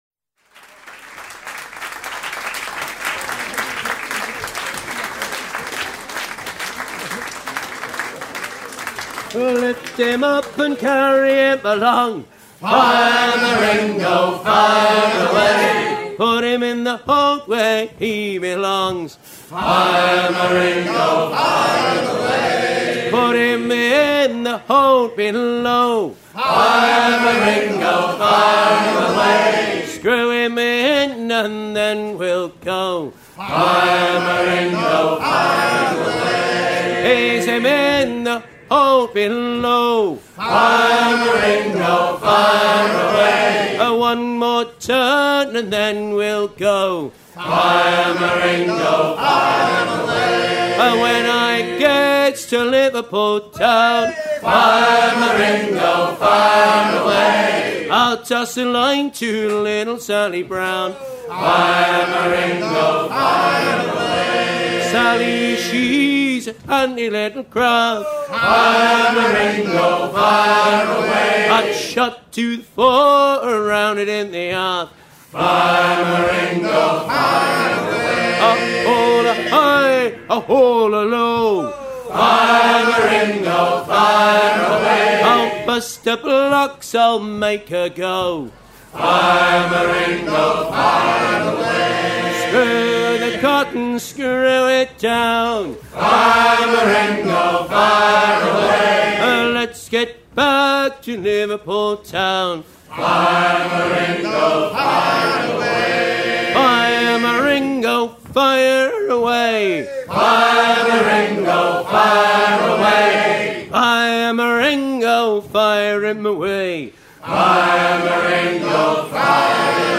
chantey pour arrimer les balles de coton
Pièce musicale éditée